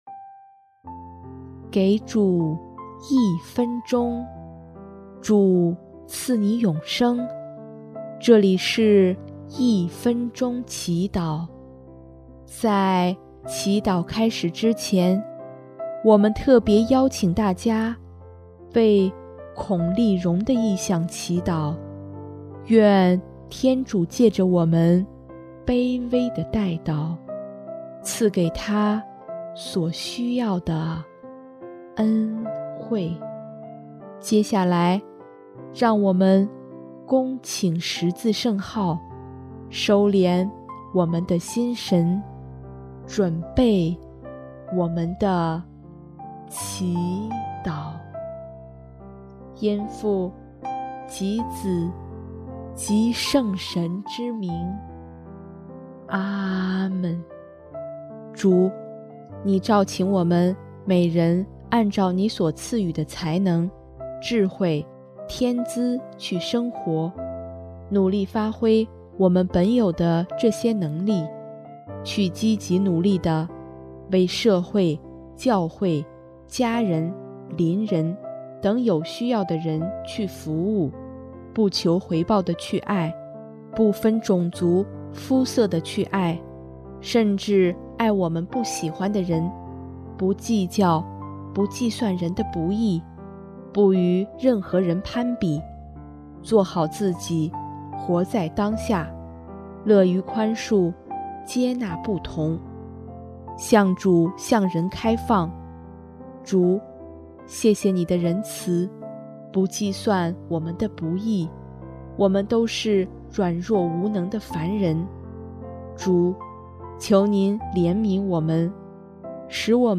音乐：第四届华语圣歌大赛优秀奖